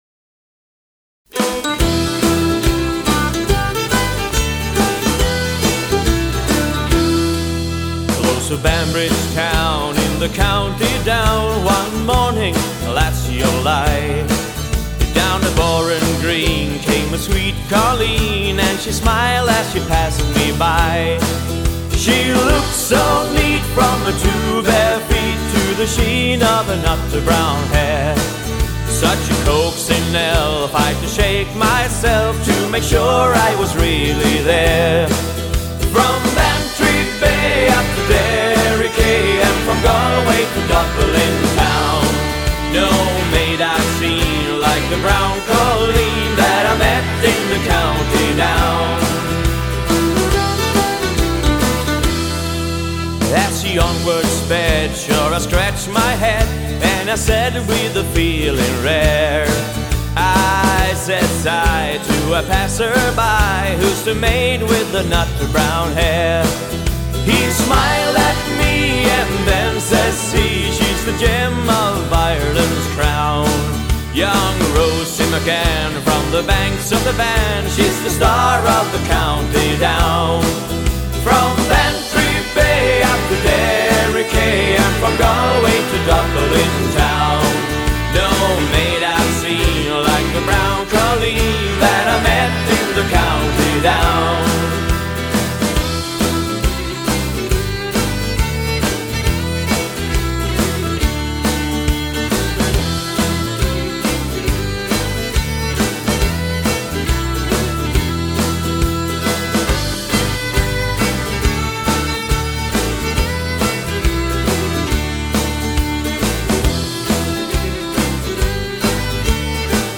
sång och mandolin
bas och sång
dragspel
fiol